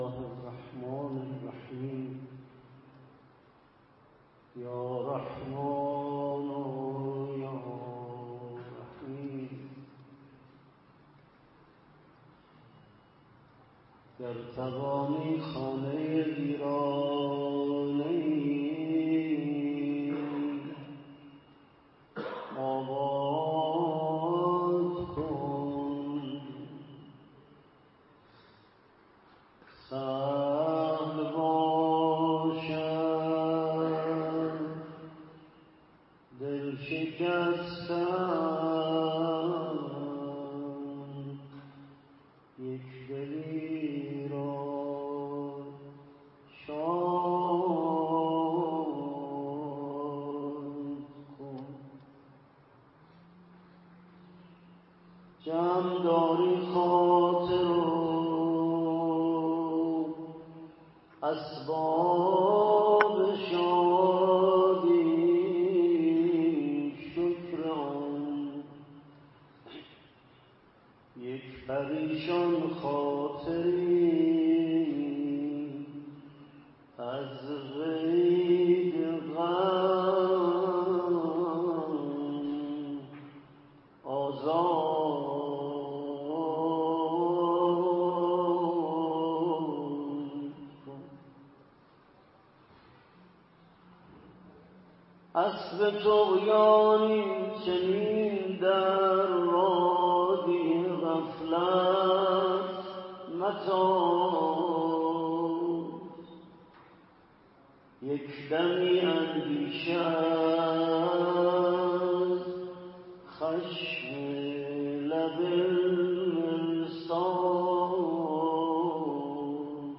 مراسم عزاداری شهادت حضرت موسی بن جعفر ع